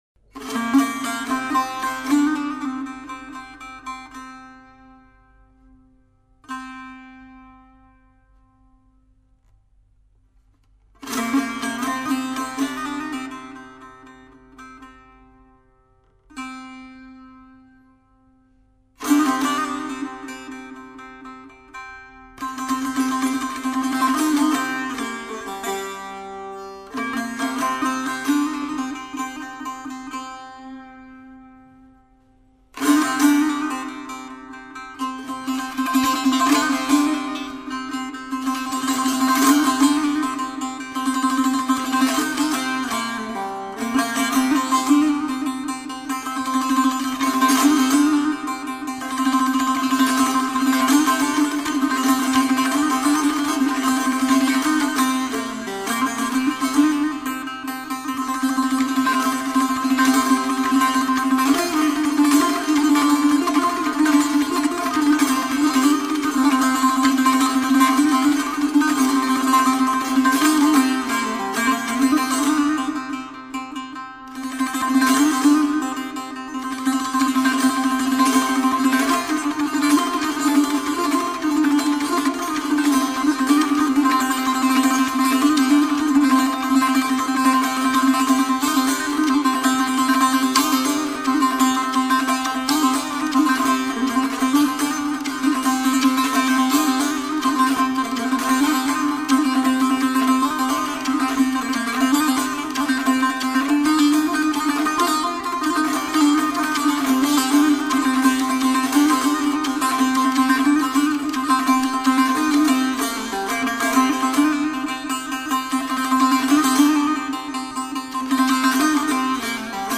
ژانر: سنتی